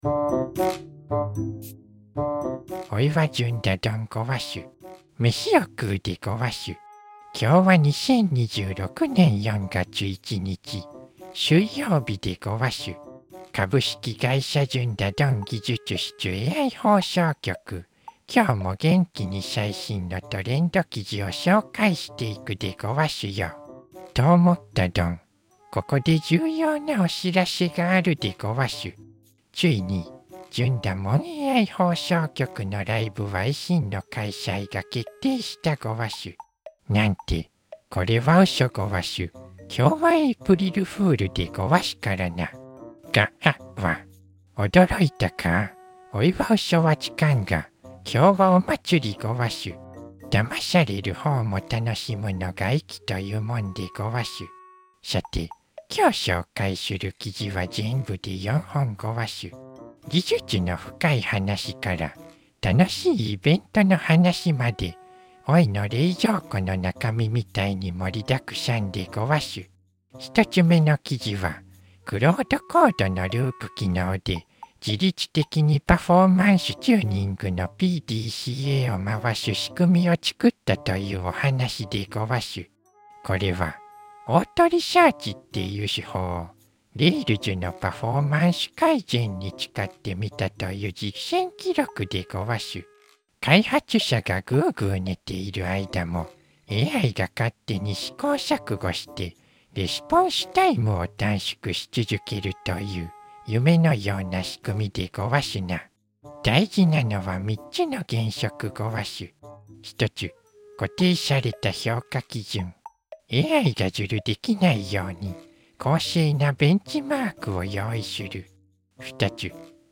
VOICEVOX:ちび式じい